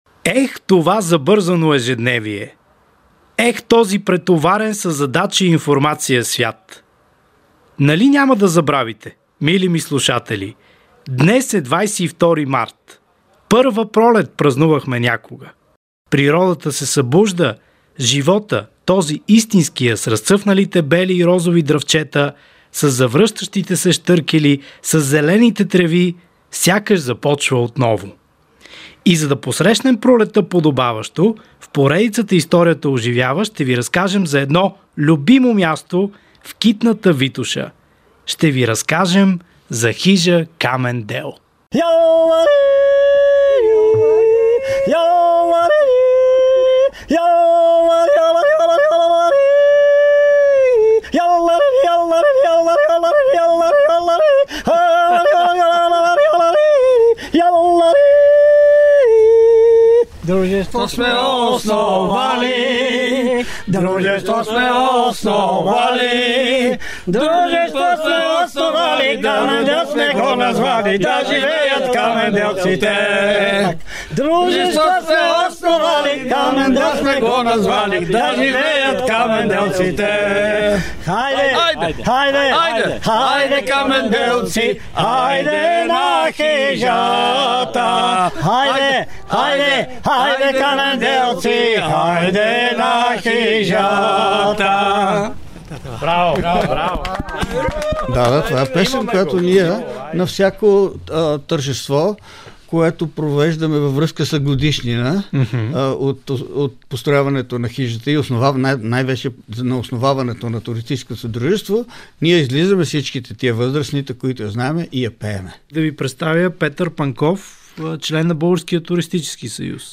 Всяка събота от 13 до 16 часа по Радио София